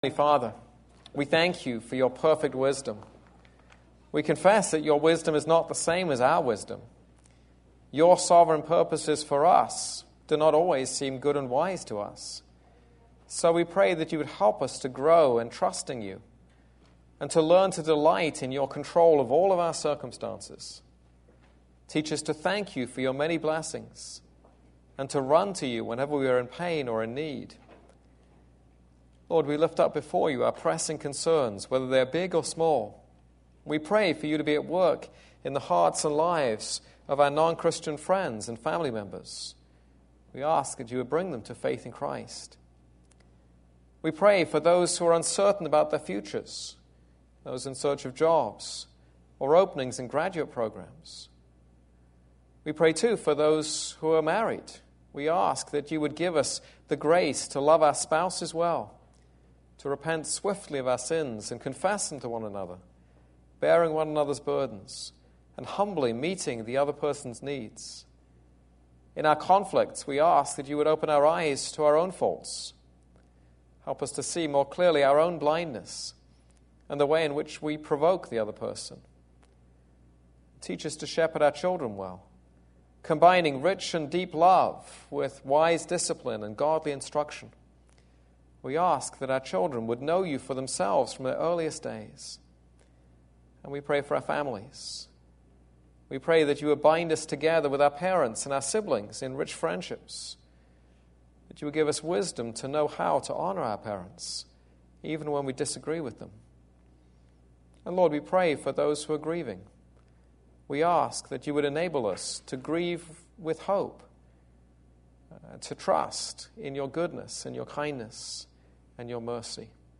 This is a sermon on Song of Songs 6:1-10.